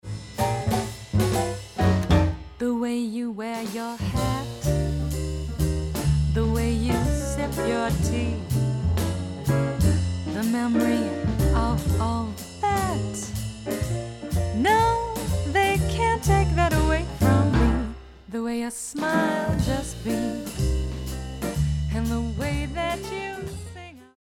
jazz standards